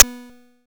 GSMNoise.wav